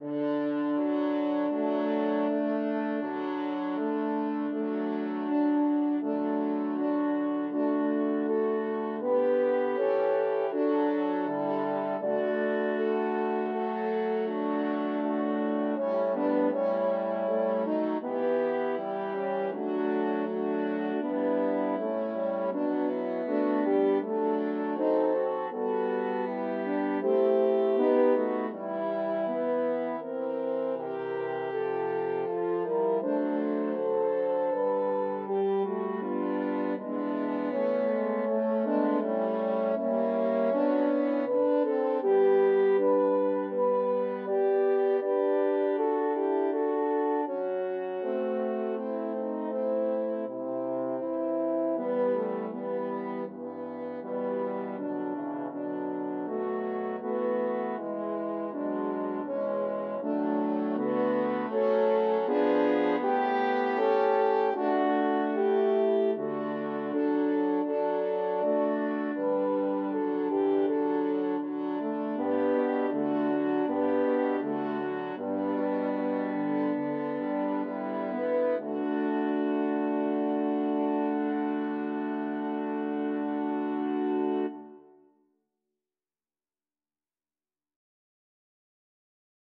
Evening Prayer (Horn Quartet)
Evening-Prayer-Horn-Quartet.mp3